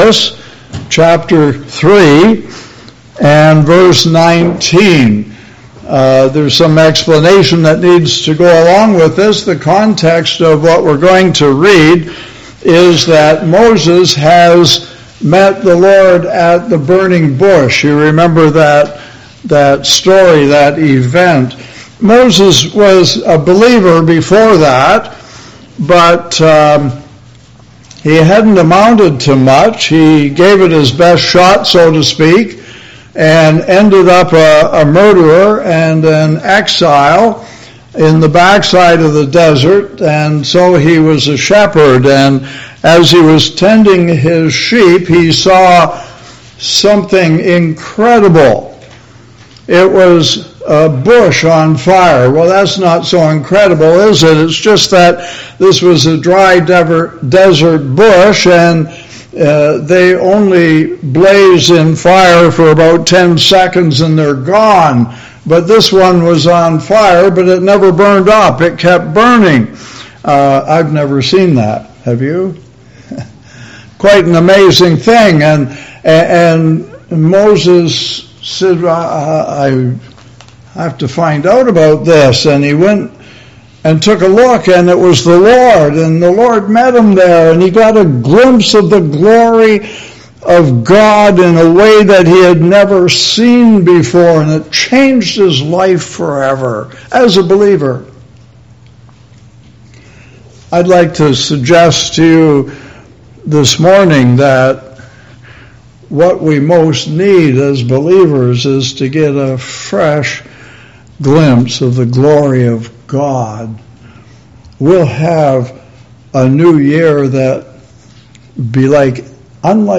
Service Type: Family Bible Hour Topics: God's Glory , God's love , lamb , obedience , power « A Kind Spirit Christ Is Better